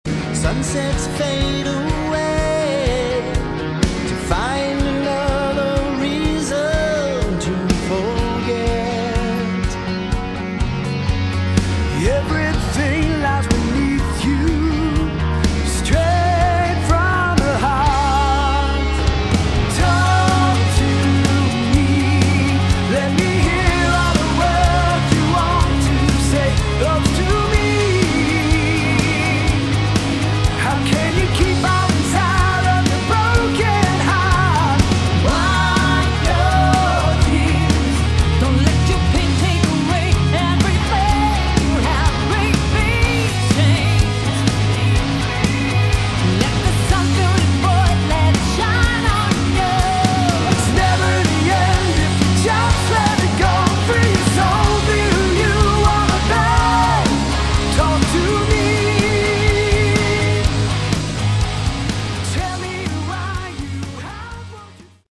Category: Melodic Hard Rock
lead vocals, drums
bass, vocals
guitars
keyboards, backing vocals
guest vocals